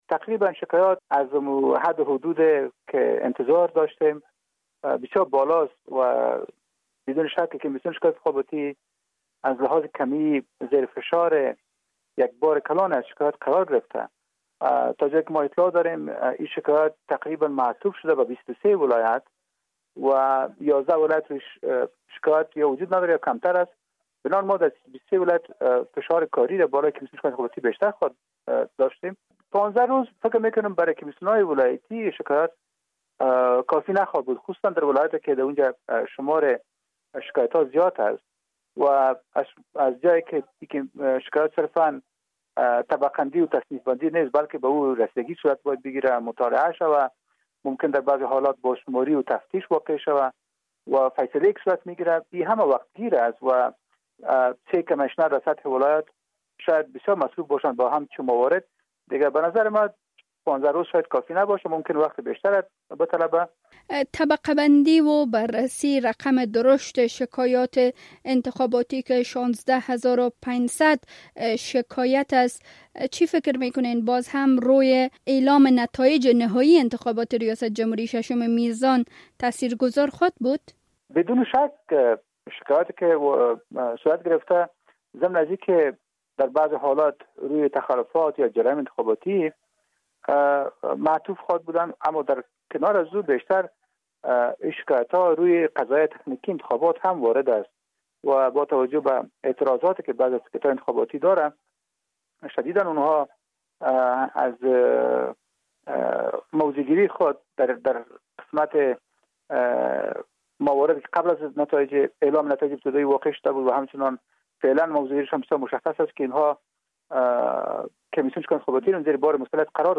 مصاحبه تلیفونی